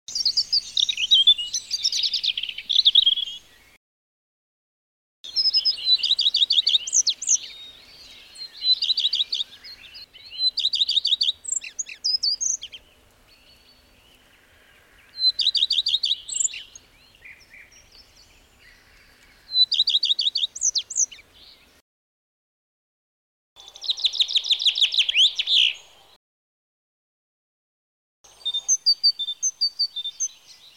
gesang-einiger-singvoegel-das-kleine-1x1-der-artenkunde.m.mp3